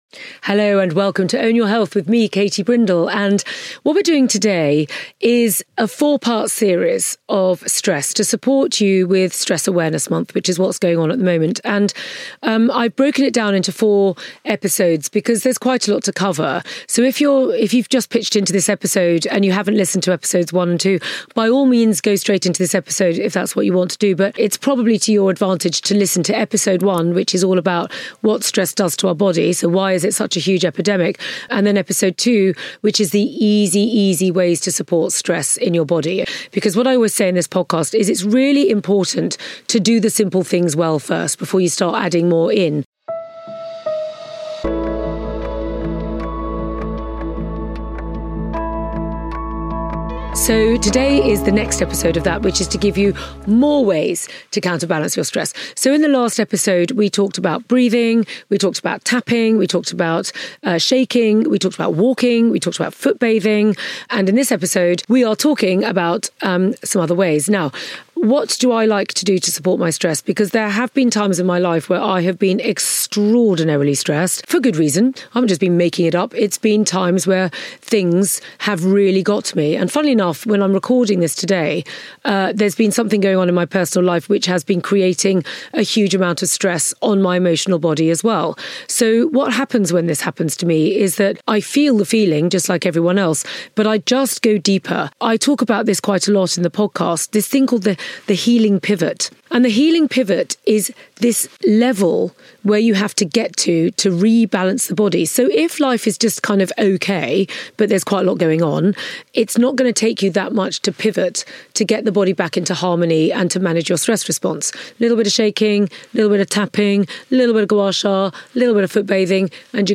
guides listeners through a meditation designed to alleviate stress.